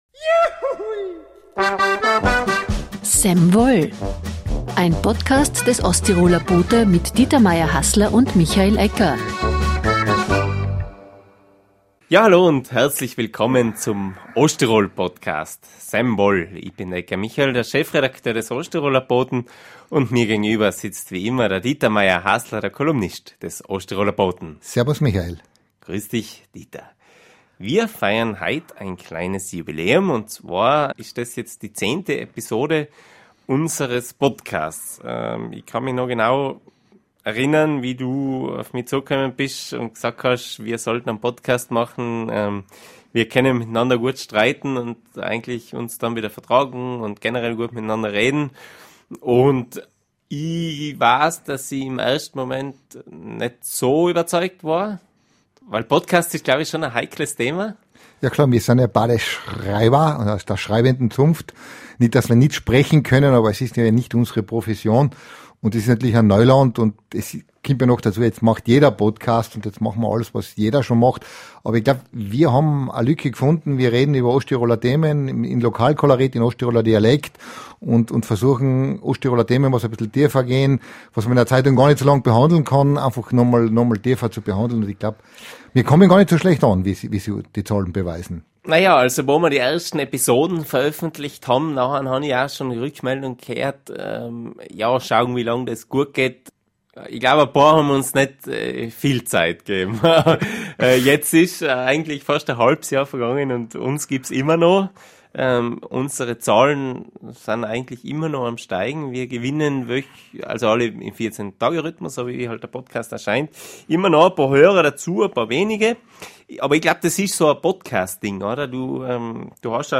Doch wie viele Osttiroler Katholiken zieht es noch in die Kirche? Ein Gespräch über Religion und Glauben im Herrgottswinkel Österreichs.